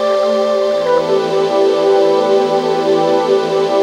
FLUTE 2 SP-L.wav